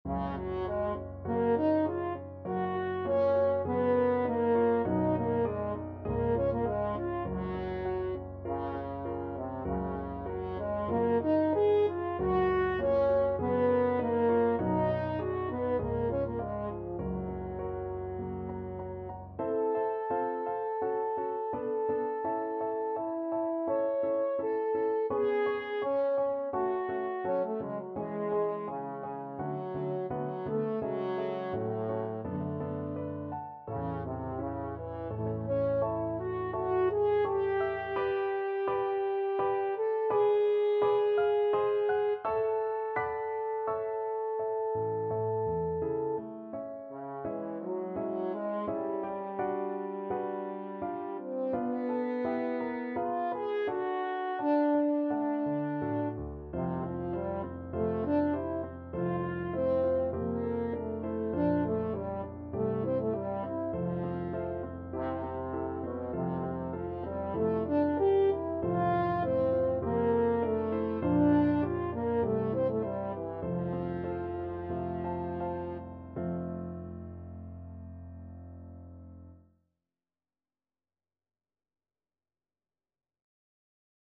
Moderato
4/4 (View more 4/4 Music)
Classical (View more Classical French Horn Music)